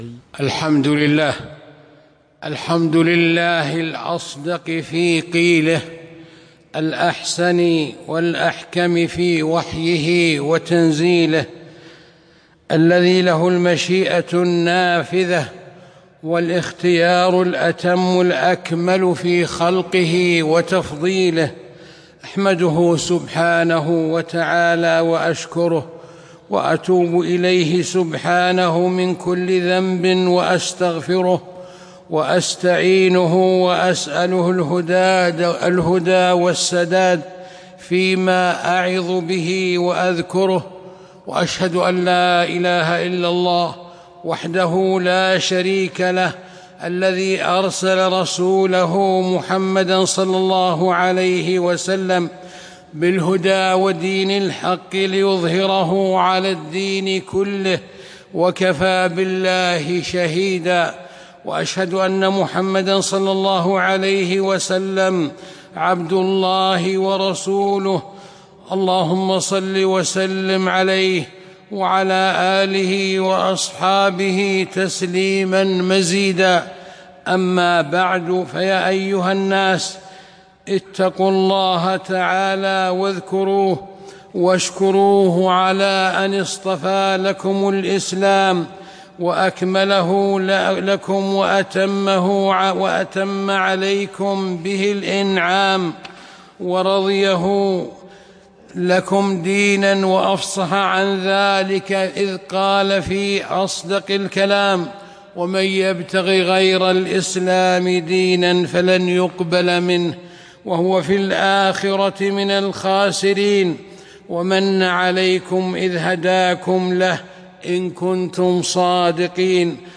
خطبة - شأن دين الإسلام، ومهمات من محاسنه والواجب نحوه